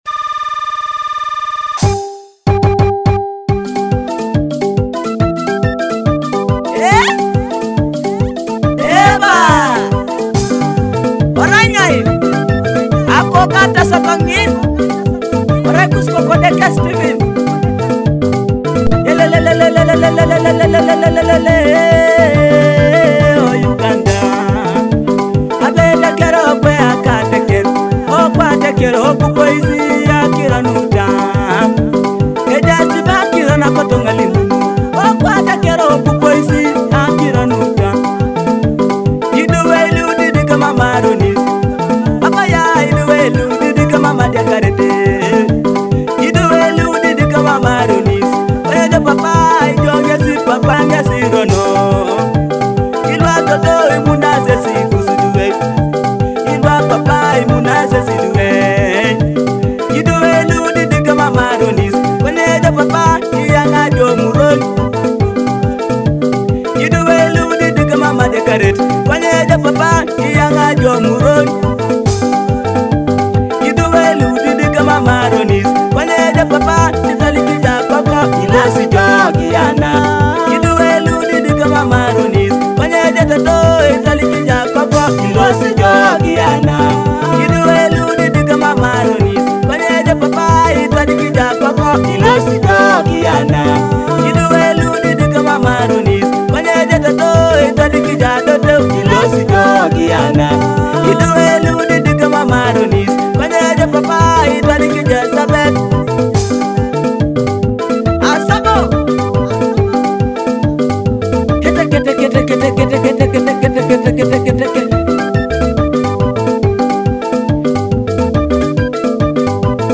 Teso cultural and traditional rhythms